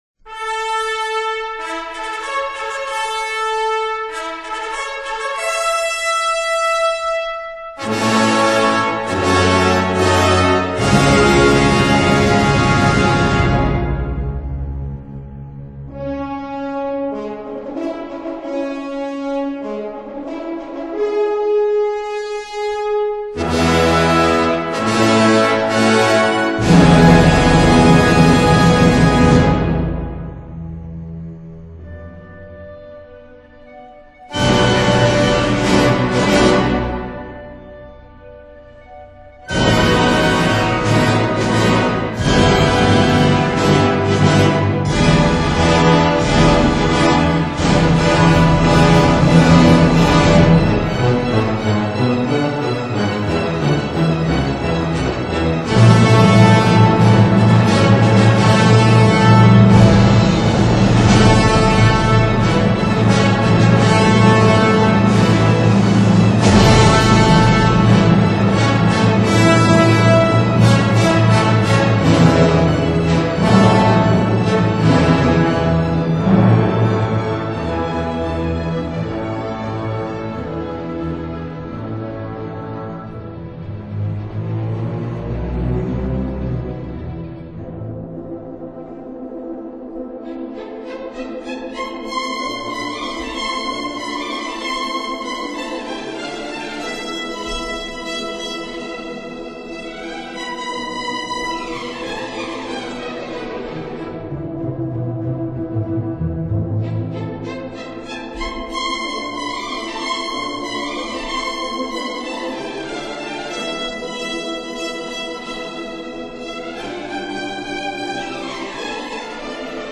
◆语言种类:纯音乐1CD
管弦乐